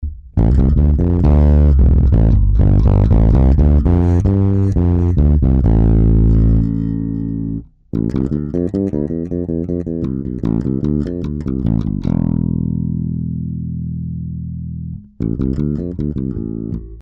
jinak ještě tady pro zajímavost jsem udělal nahrávku basy uplně naplno a uplně na nule...